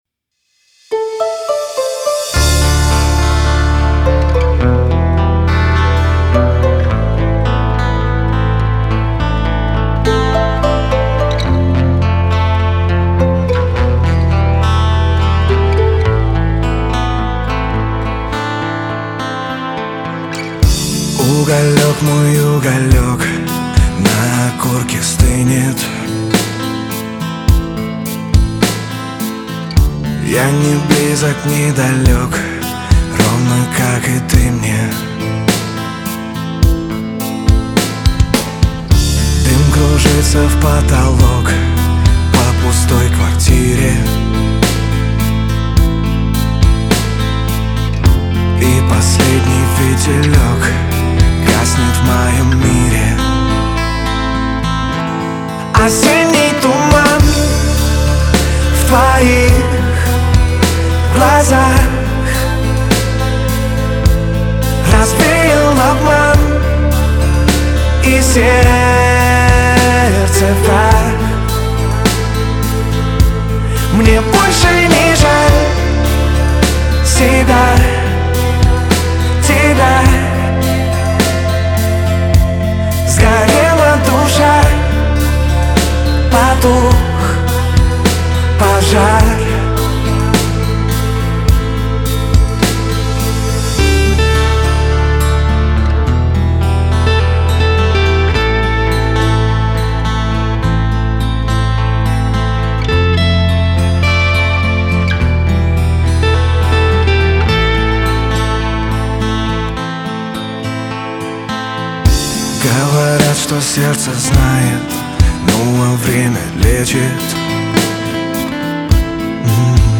медленные песни